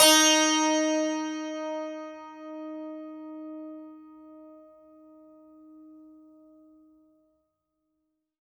52-str06-sant-d#3.wav